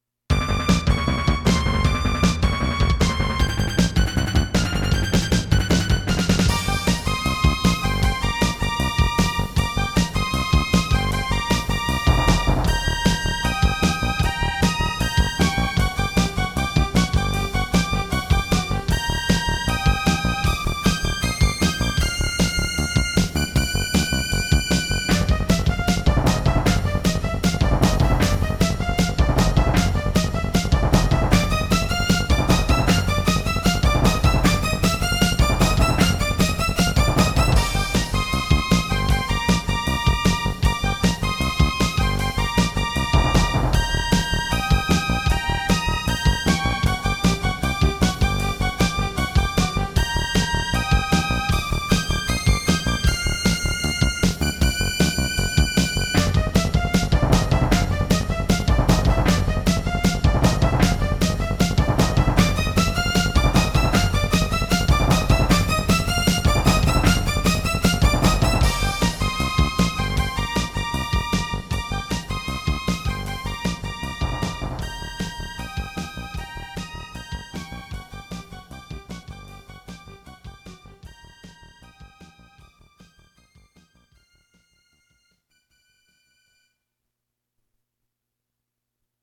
As recorded from the original Roland MT-32 score